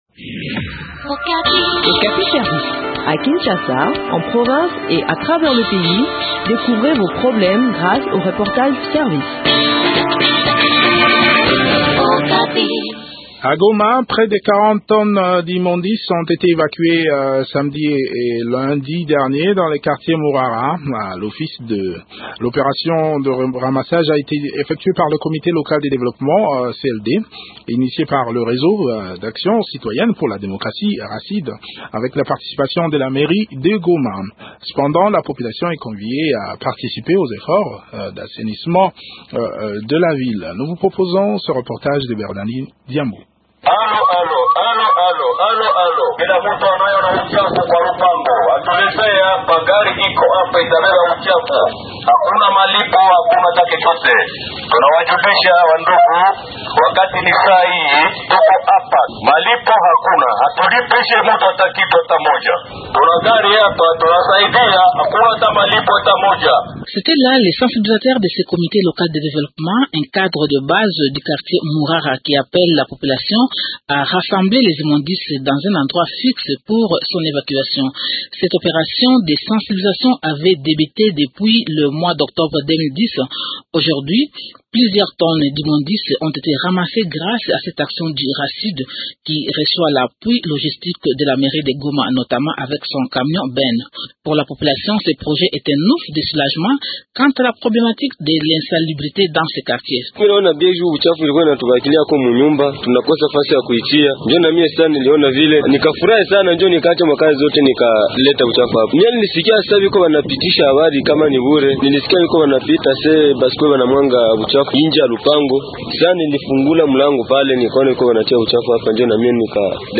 Le point sur le déroulement de l’opération dans cet entretien